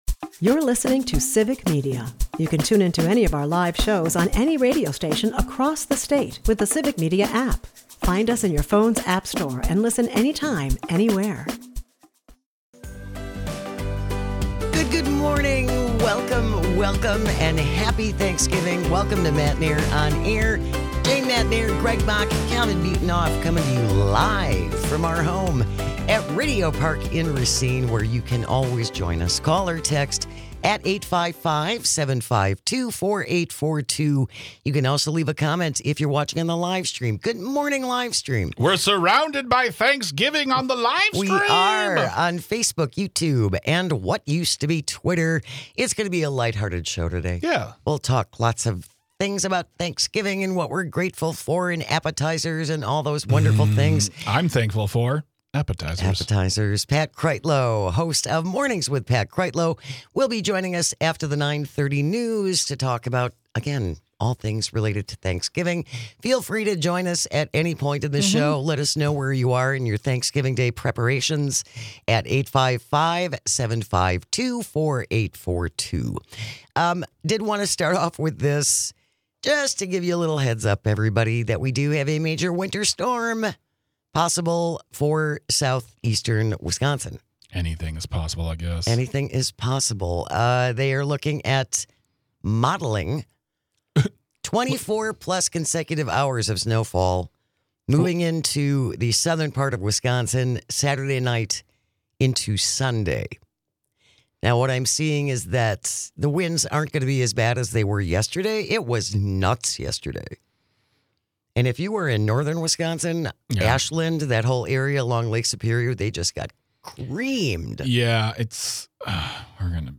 They discuss the joys and challenges of holiday prep, including cooking tips like sous-vide turkey, and the importance of kindness in customer service. Weather alerts hint at a snowy weekend for Wisconsinites, urging caution for travelers. The show takes a humorous turn with clips of Trump’s bizarre turkey pardon speech and JD Vance’s turkey rant.